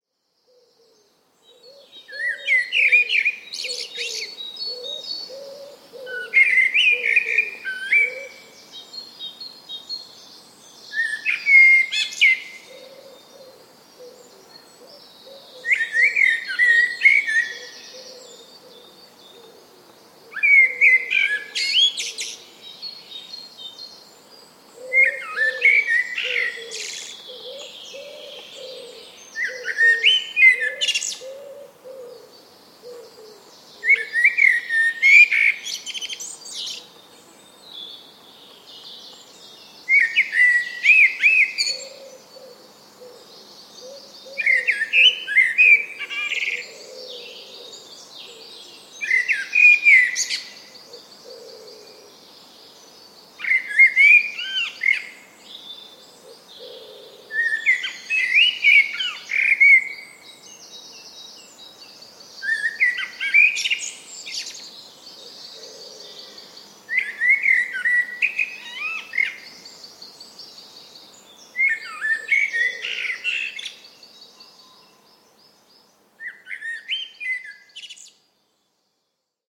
Blackbird in the Woods